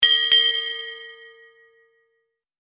Звуки китайского колокольчика
На этой странице собраны звуки китайских колокольчиков — нежные, мелодичные и наполненные восточным колоритом.
Звук звук 2 раза